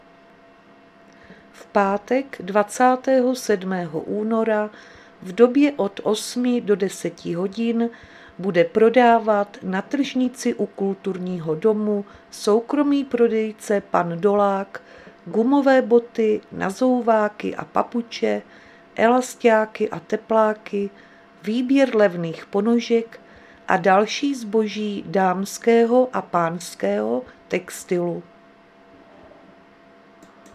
Záznam hlášení místního rozhlasu 26.2.2026